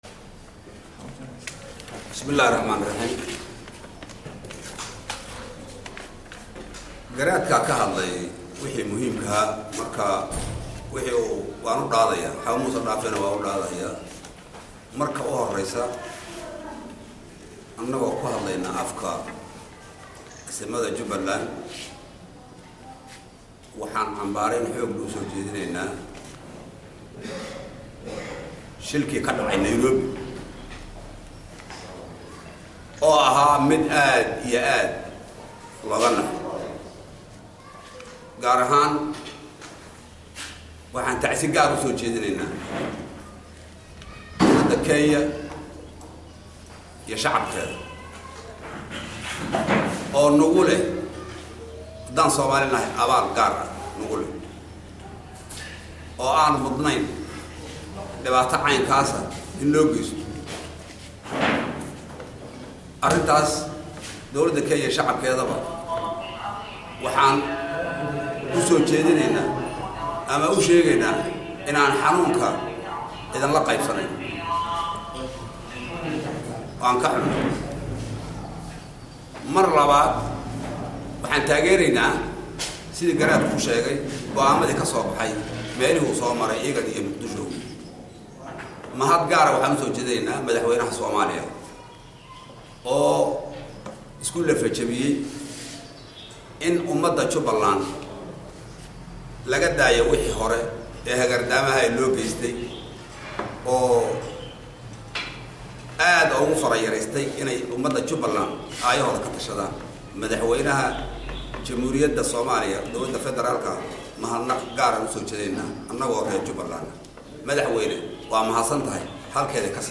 oo kahadlaya waxyaabaha ay isku afgarteen Odayasha dhaqanka jubooyinka Ee maanta kismaayo ku kulmay.